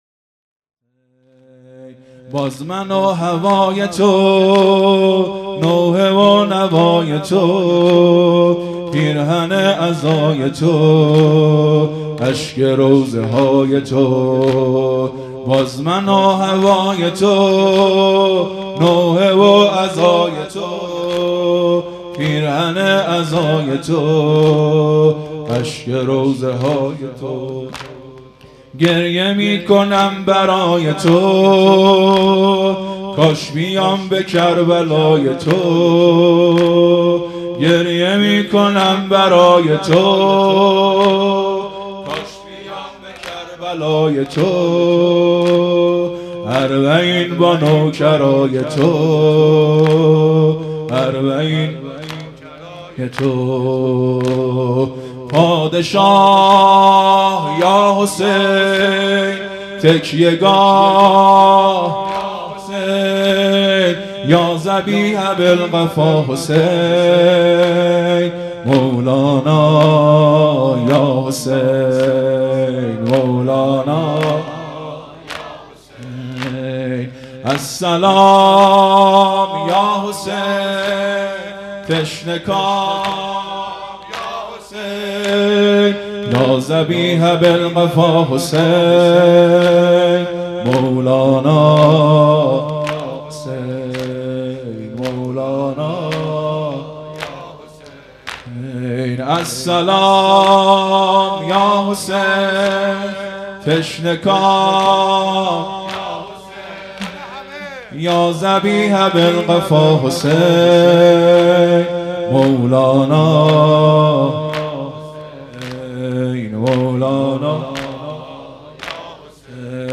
مراسم عزاداری محرم الحرام